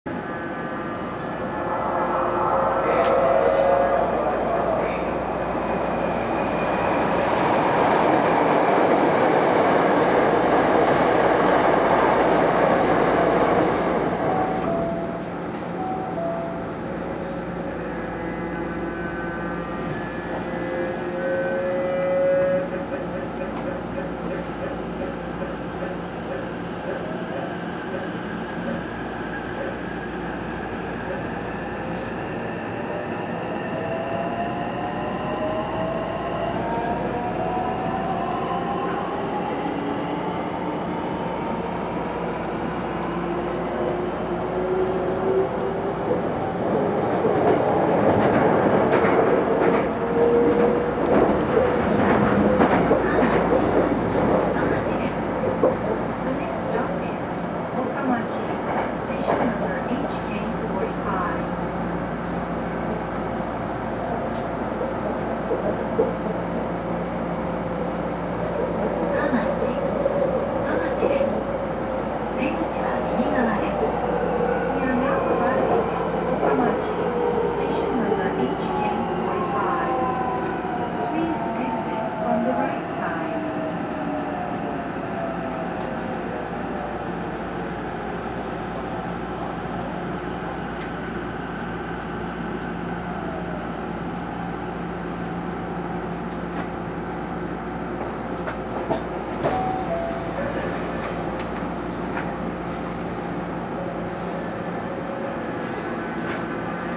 走行機器はIGBT素子による純電気ブレーキ対応のVVVFインバータ制御で、定格200kWのモーターを制御します。
走行音
録音区間：曽根～岡町(お持ち帰り)